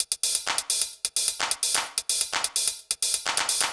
TEC Beat - Mix 8.wav